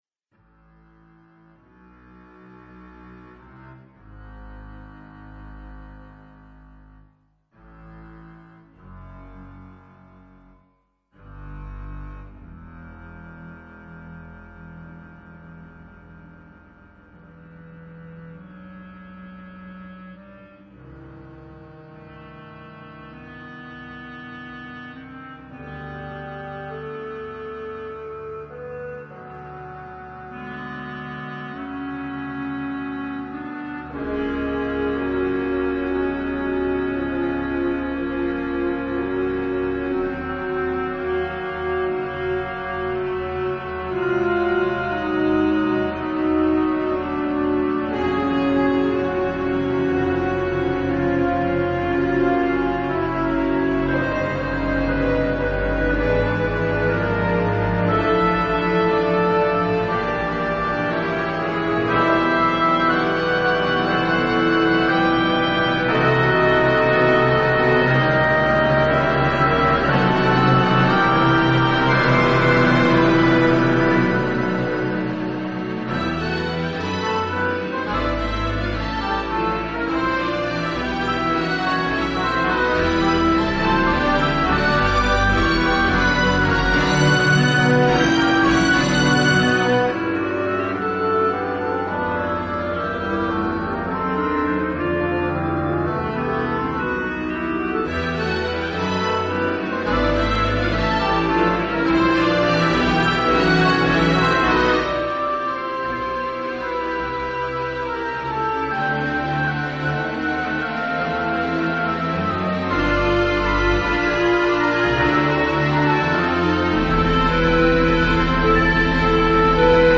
Nous présenterons l’œuvre plus complètement dans un prochain billet, mais sachez qu’il s’agit d’une belle musique symphonique, sombre et même tragique (sans être funèbre). Elle débute par une plainte sourde dans le três grave, une sorte de grondement de la clarinette basse, qui enfle en un crescendo impressionnant jusqu’au tutti, se répète, puis s’apaise, devient gémissement plaintif, musique oppressante, hypnotique qui s’enfle à nouveau jusqu’au râle final.